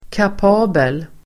Uttal: [kap'a:bel]